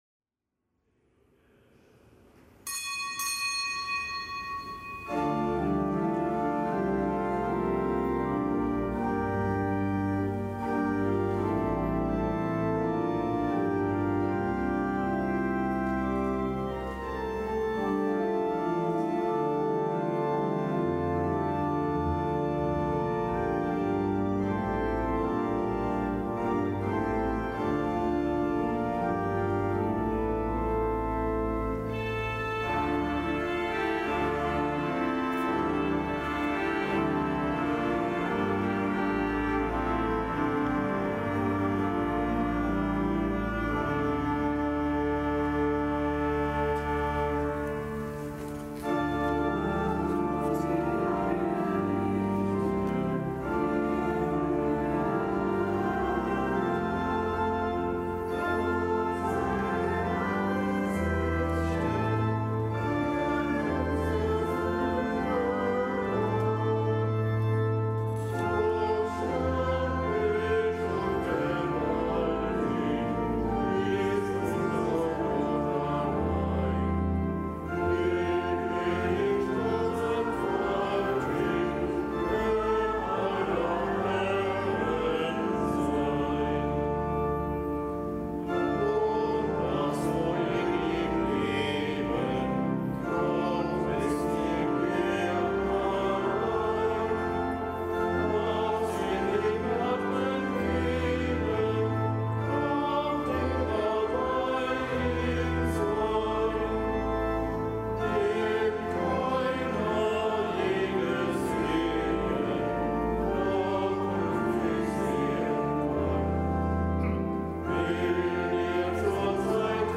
Kapitelsmesse am Montag der sechzehnten Woche im Jahreskreis
Kapitelsmesse aus dem Kölner Dom am Montag der sechzehnten Woche im Jahreskreis, dem nicht gebotenen Gedenktag des heiligen Laurentius von Bríndisi, einem Ordenspriester und Kirchenlehrer (RK;GK).